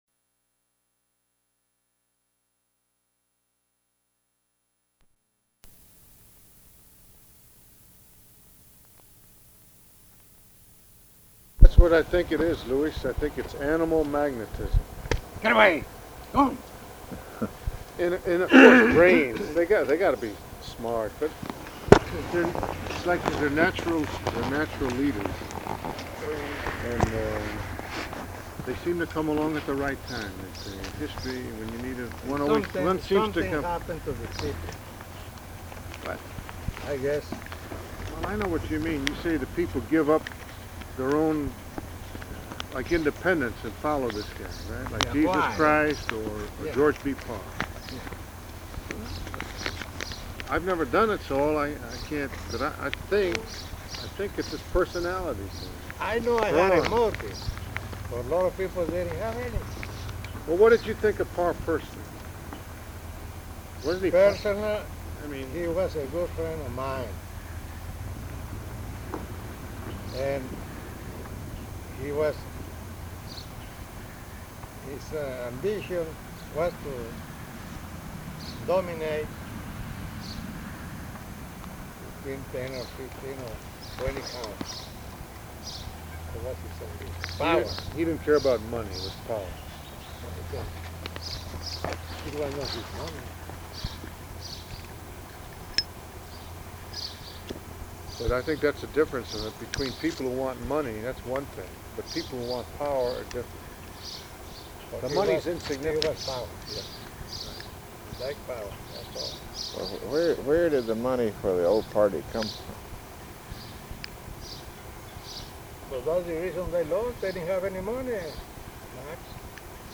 Outdoors; a lot of it is difficult to hear
Specific Item Type Interview Subject Congressional Elections Texas